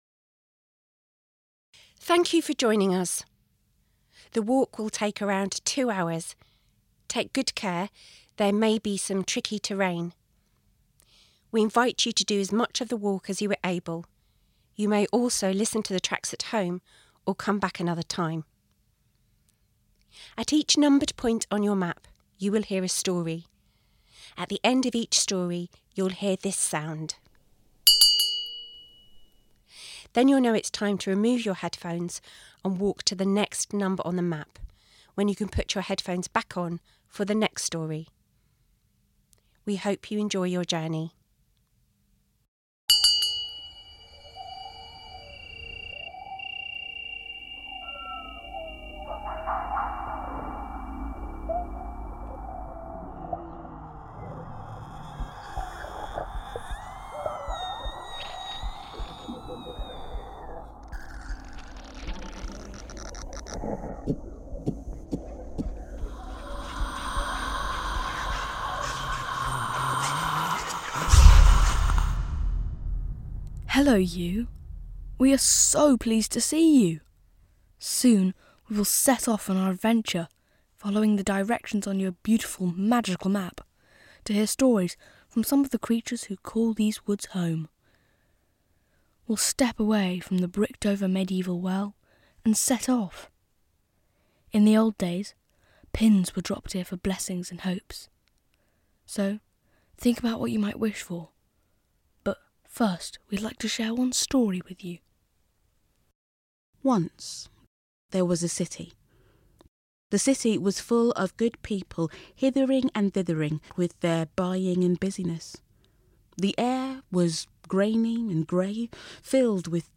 Lore of the Wild – an audio storytelling walk through the woods